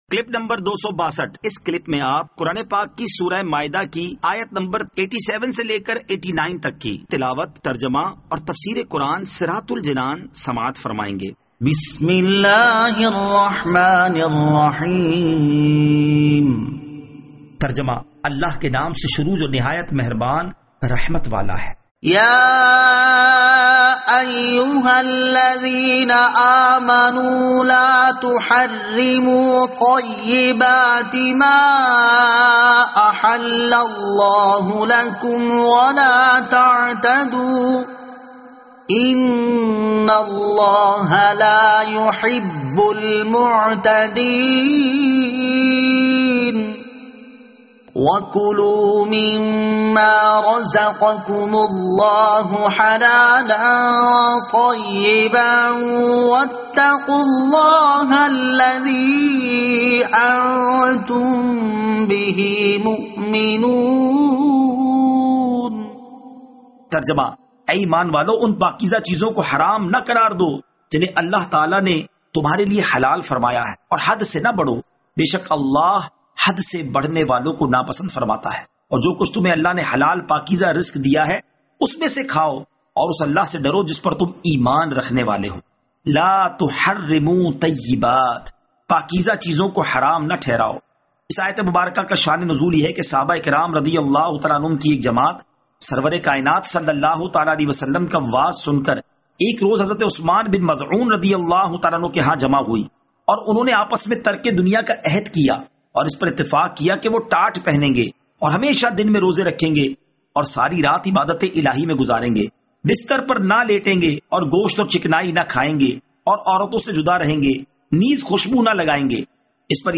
Surah Al-Maidah Ayat 87 To 89 Tilawat , Tarjama , Tafseer